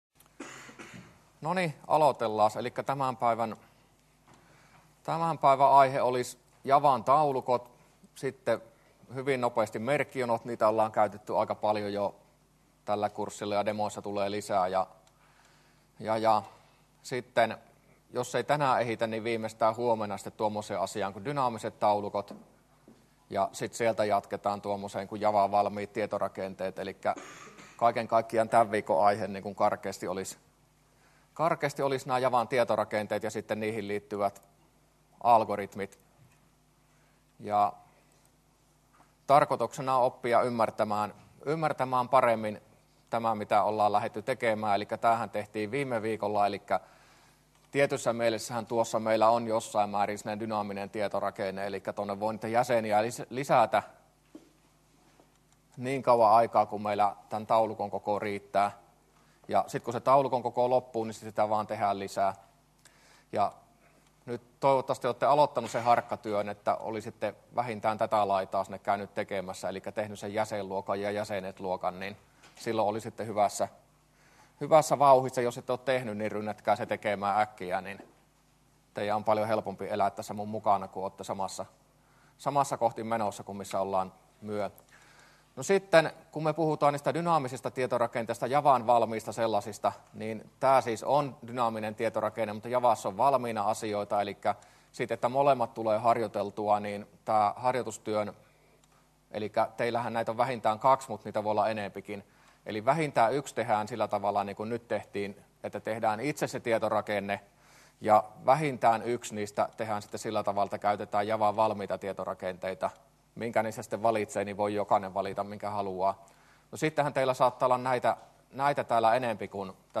luento15a